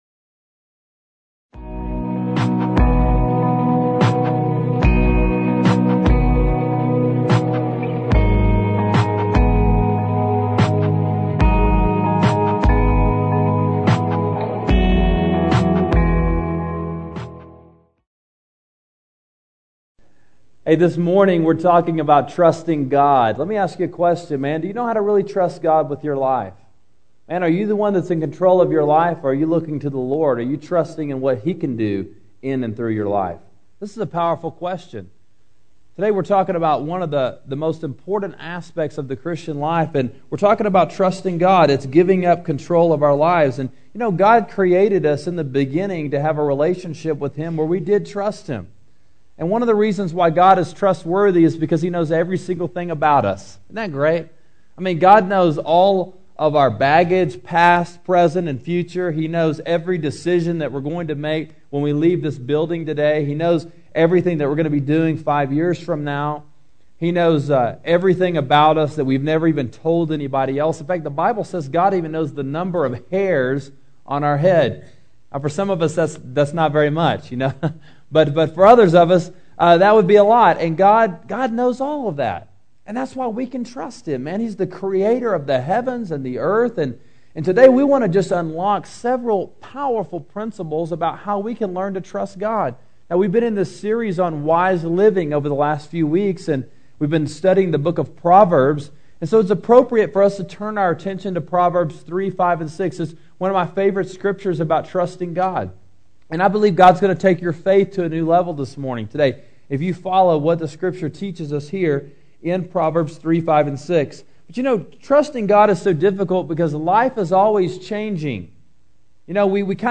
Mastering The Art Of Living: Trusting God When You Feel Like Quitting-Proverbs 3:5-6 – Sermon Sidekick